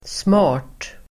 Uttal: [sma:r_t]